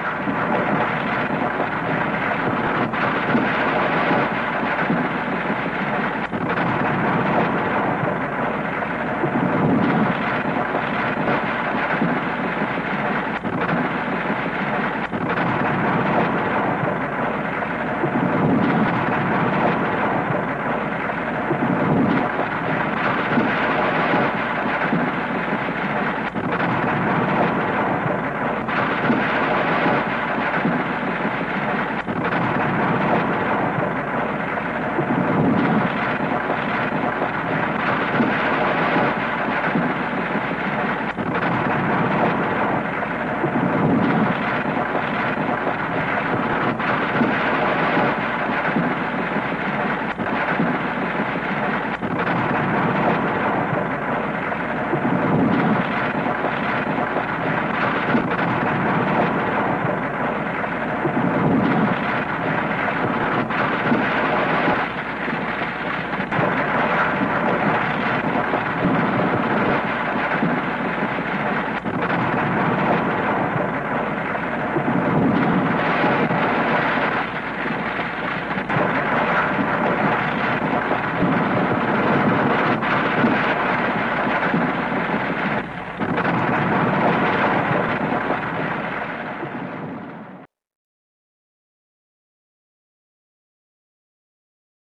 地震効果音約 90秒（WAV形式 約16MB）
地震効果音はフリー音源を上記の秒数に編集したものです。